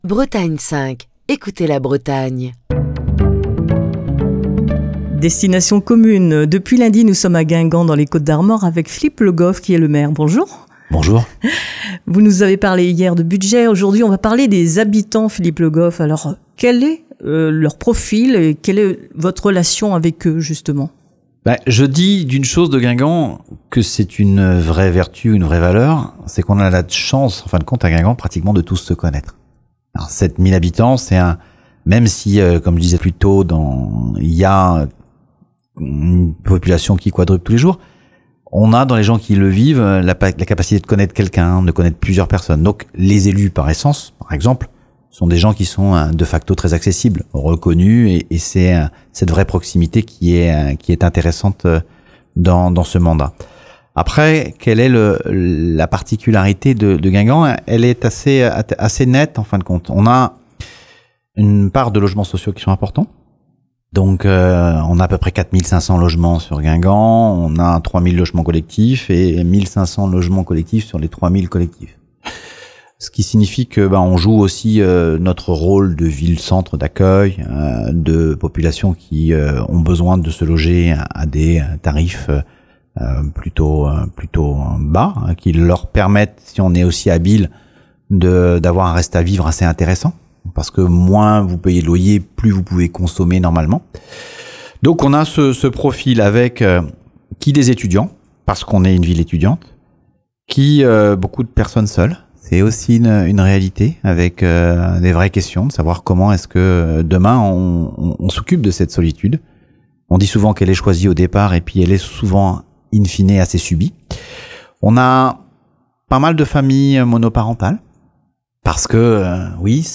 Philippe Le Goff, le maire de Guingamp